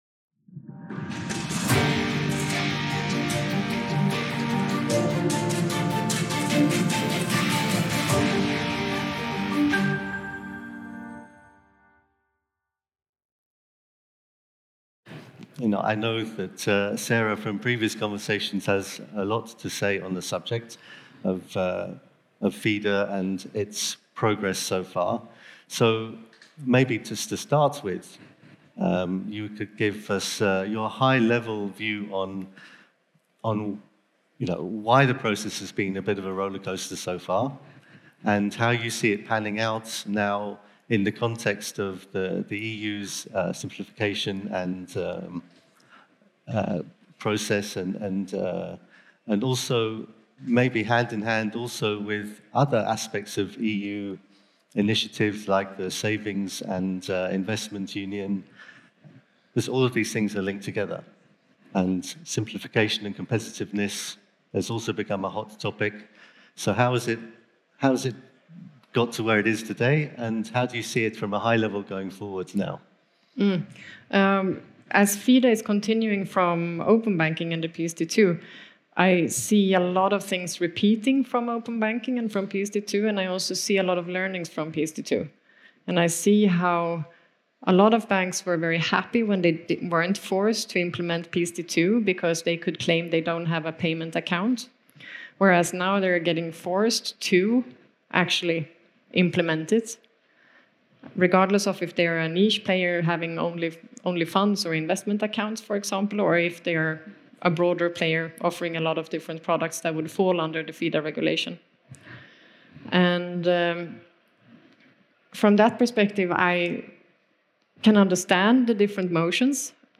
Listen to this insightful conversation where two industry experts share perspectives, trends, and strategies shaping the future of financial services.